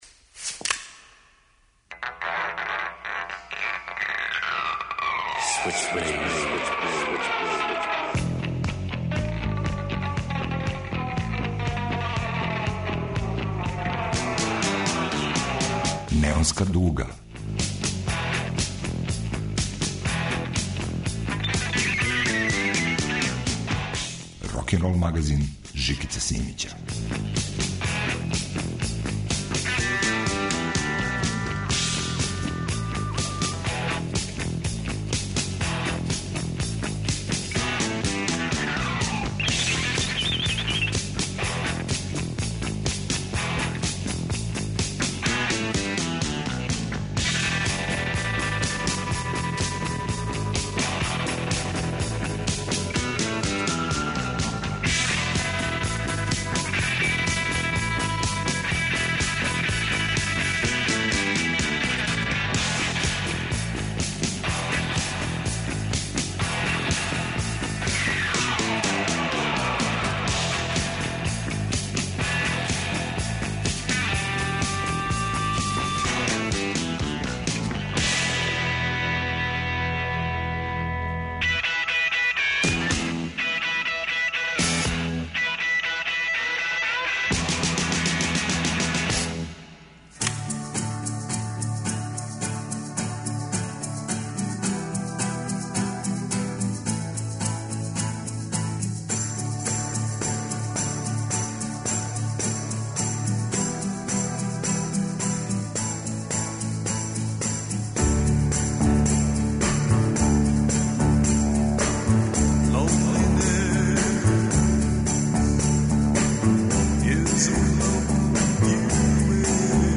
Рокенрол као музички скор за живот на дивљој страни. Вратоломни сурф кроз време и жанрове. Старо и ново у нераскидивом загрљају.